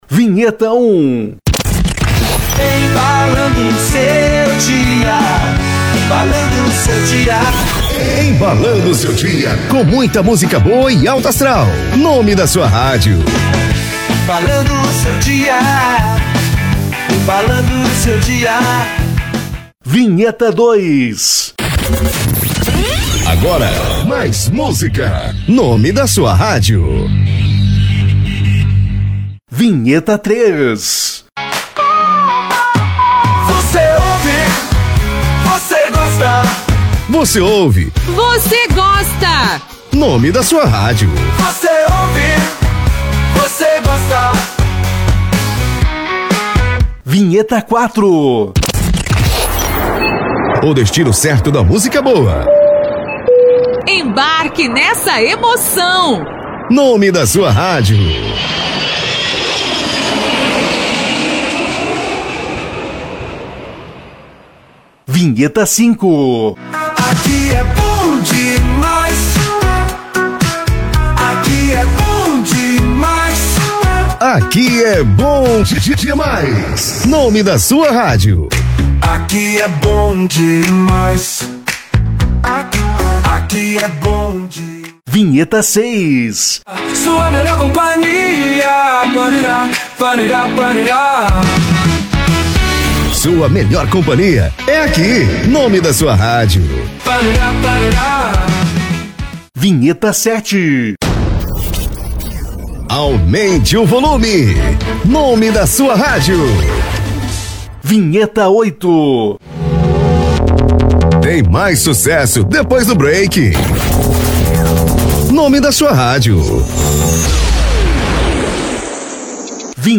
12 vinhetas gravadas com o nome da sua rádio, estilo jovem e pop rock!